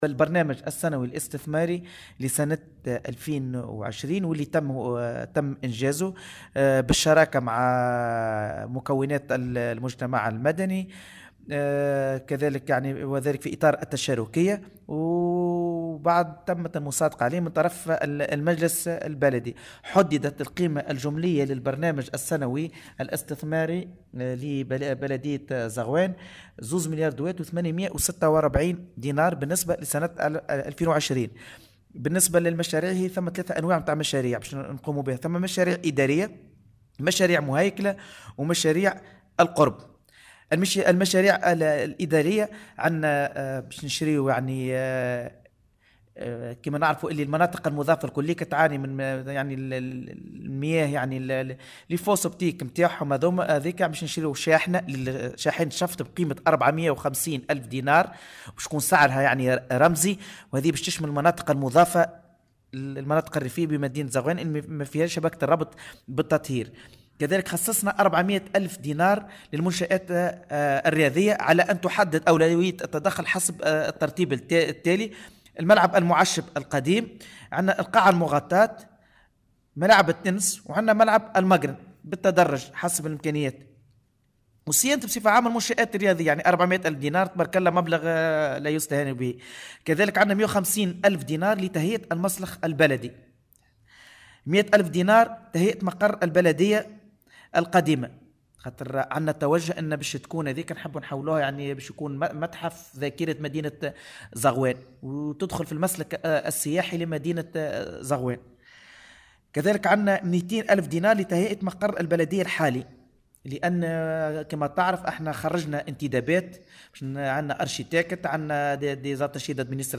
وقد خصصت لفائدة هذا المشروع اعتمادات قدرها 100 ألف دينار، وفق ما أكده رئيس بلدية زغوان، طارق الزوقاري لمراسلة "الجوهرة أف أم".